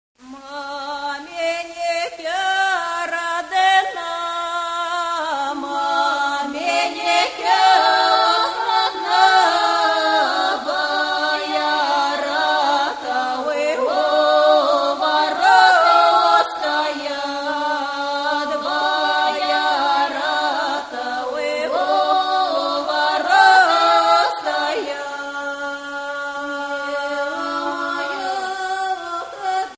vocal, solo, Okarina (ocarina)
- Traditional songs of Siberia
bridal song